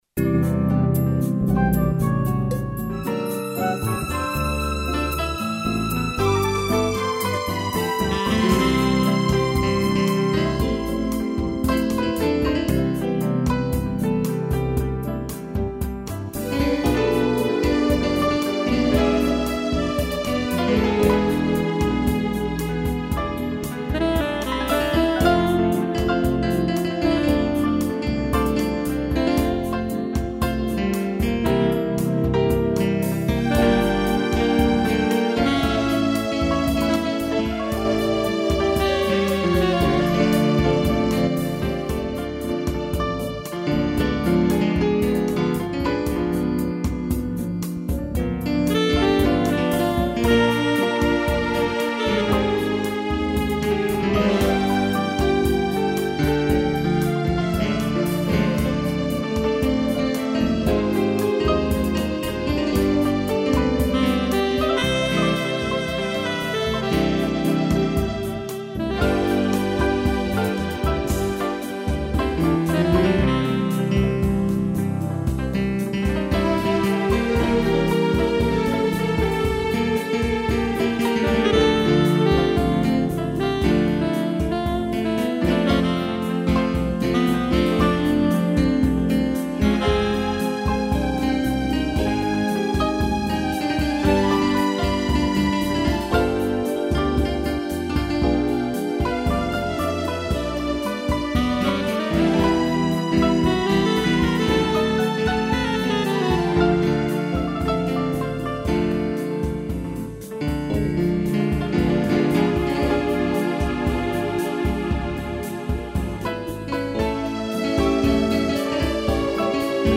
piano, sax e violino